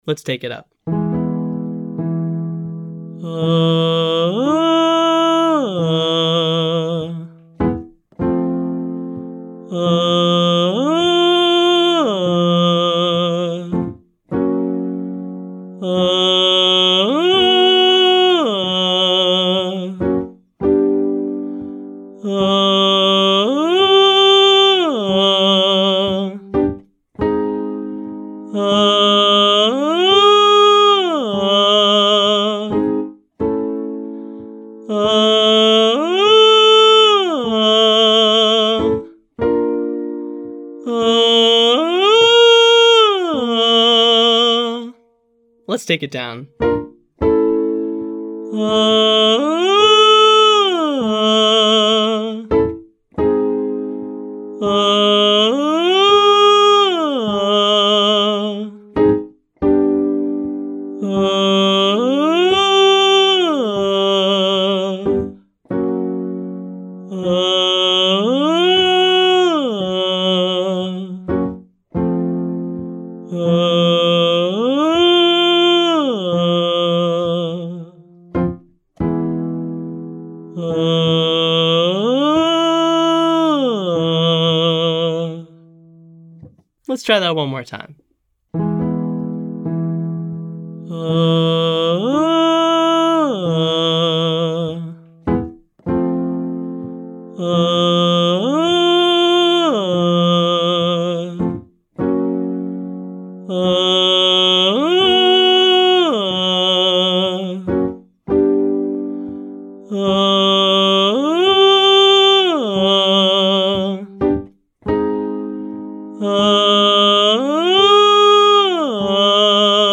Same pattern, now on a slightly more open vowel.